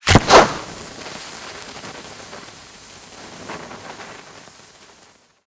Incendiary_Near_02.ogg